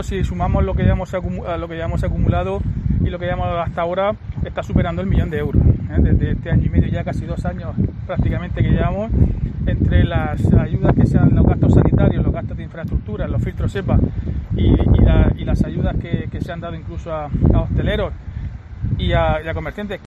Diego José Mateos, alcalde de Lorca sobre gastos COVID